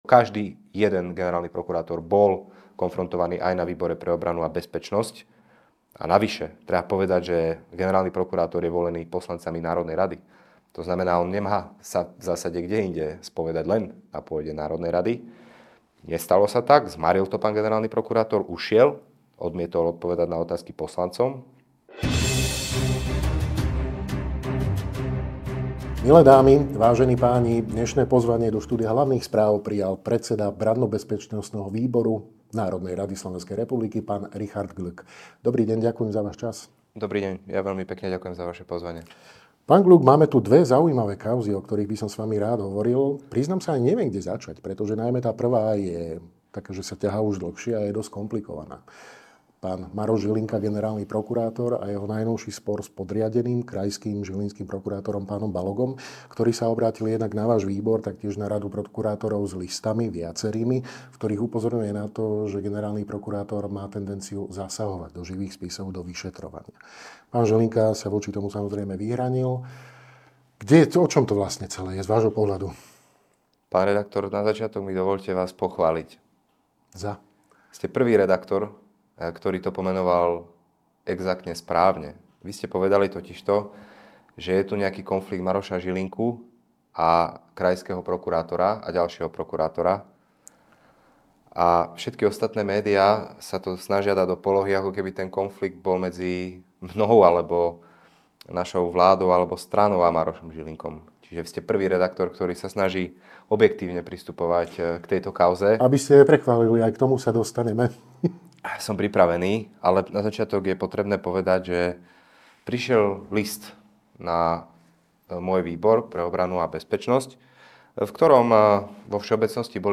Dozviete sa vo videorozhovore s predsedom Brannobezpečnostného výboru NR SR, JUDr. Richardom Glückom (nahrávané 26.11.2025).